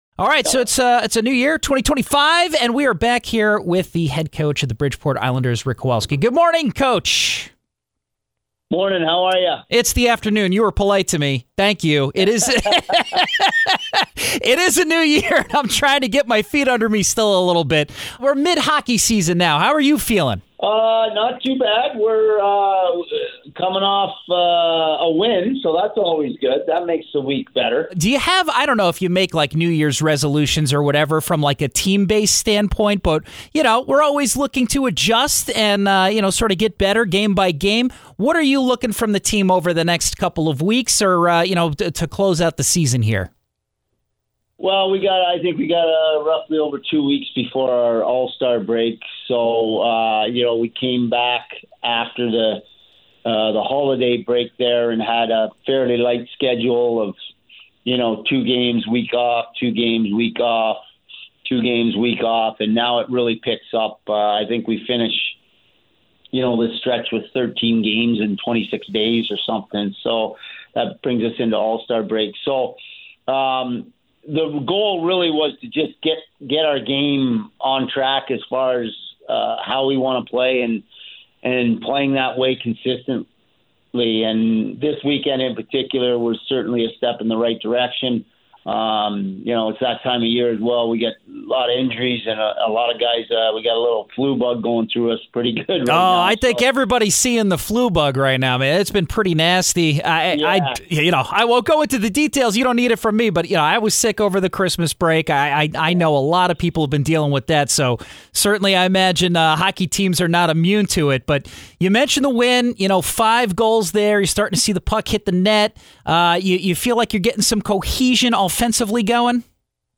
on the phone